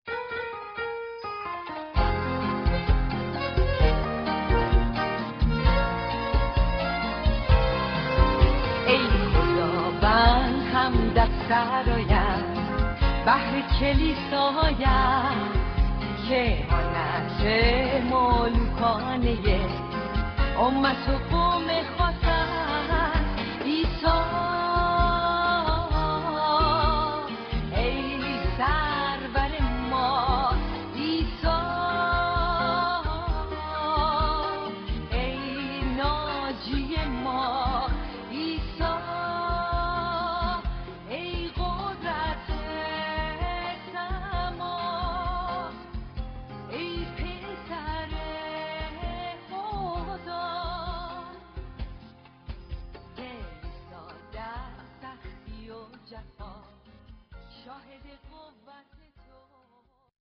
POP Persian (Farsi) Christian Music